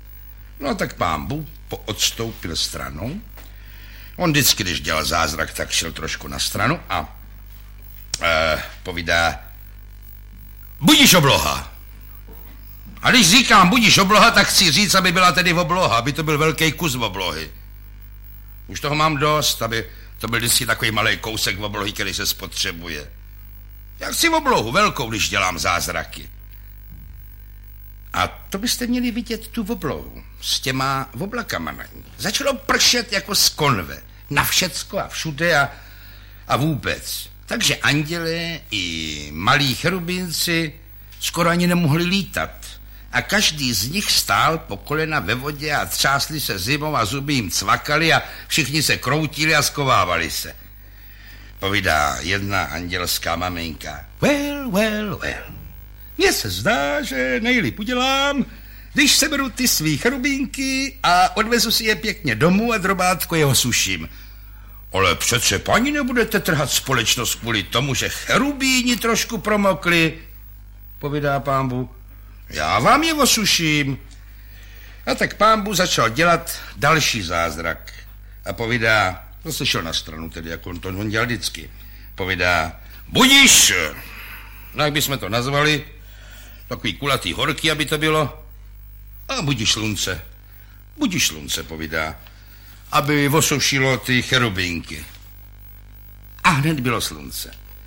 Audio kniha
Ukázka z knihy
Záznamy pocházejí z archivu Ondřeje Suchého, některé z nich tvořil Jan Werich pro Suchého rozhlasový pořad Gramotingltangl.